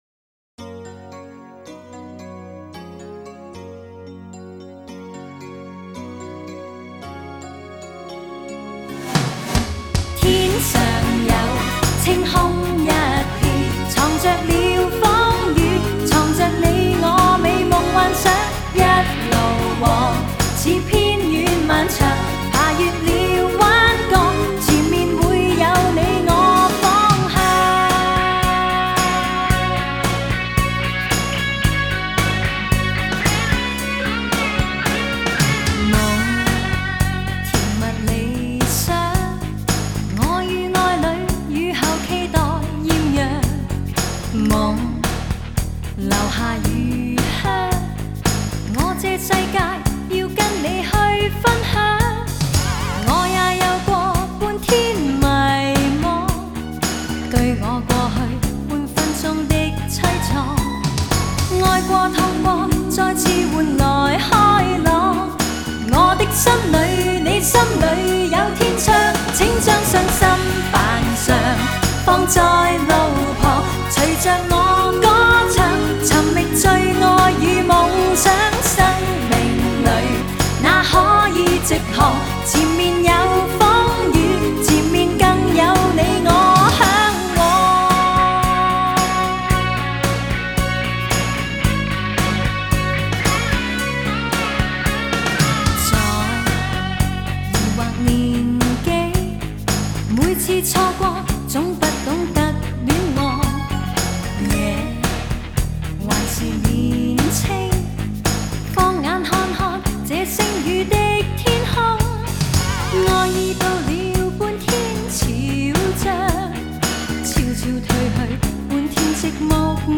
类别: 流行